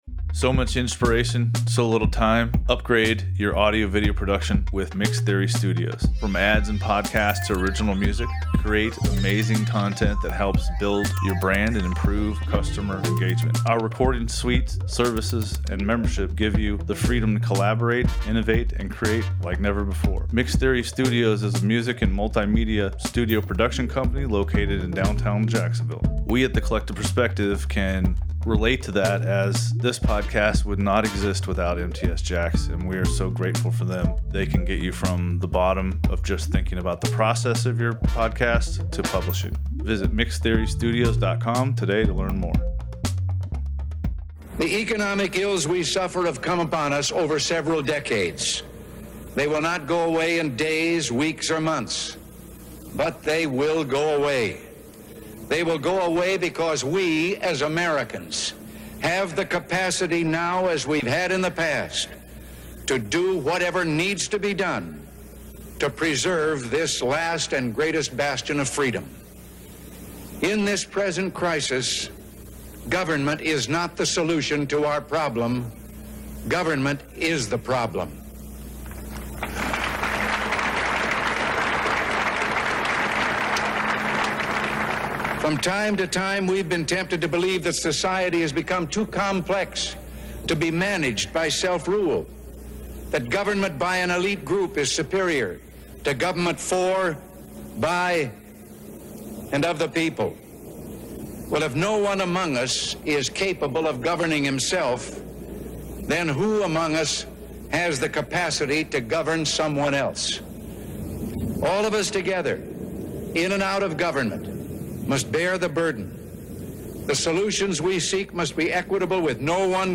This episode features a clip of the First Inaugural Presidential Address made by President Reagan at the U.S. Capitol Building on January 20, 1981.